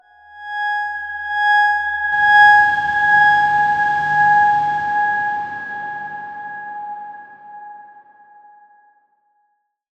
X_Darkswarm-G#5-pp.wav